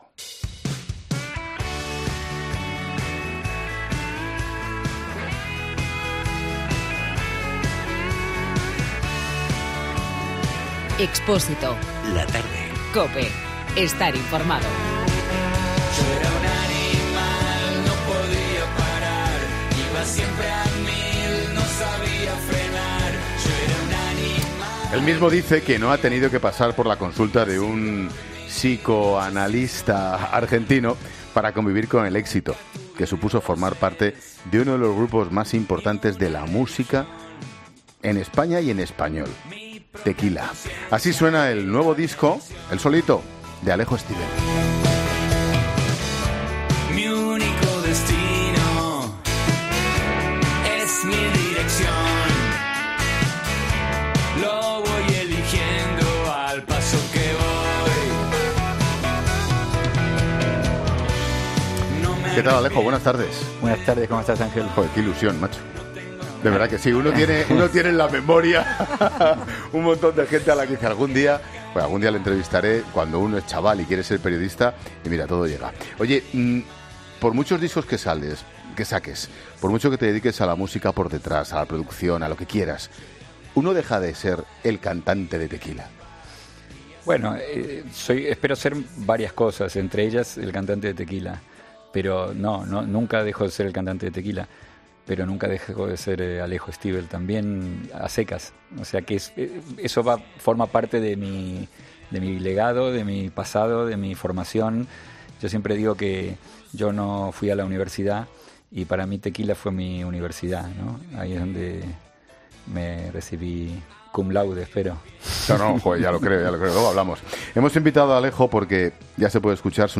ESCUCHA LA ENTREVISTA COMPLETA | Alejo Stivel en 'La Tarde' “ No he dejado de ser cantante de Tequila , para mi eso fue una universidad.